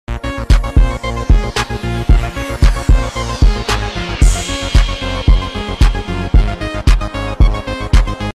Jazz Sound Effects Free Download